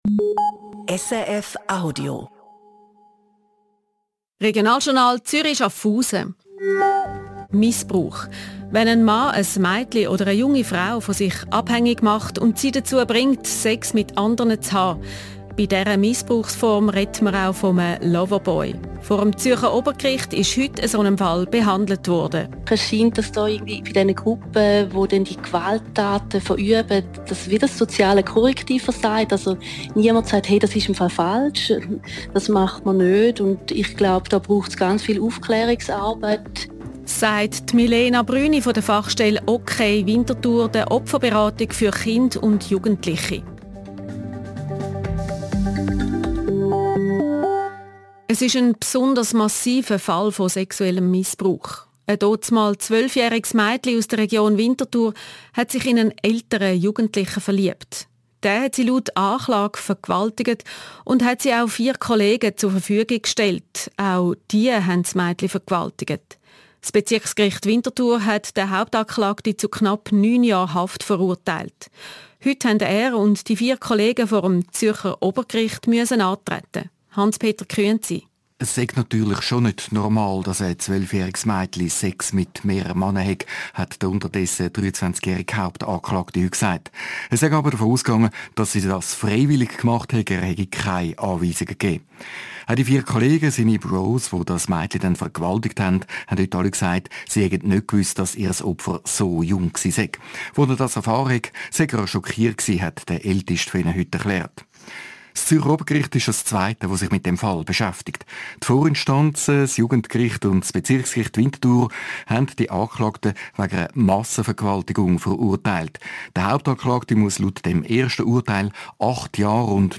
Radiointerview Regionaljournal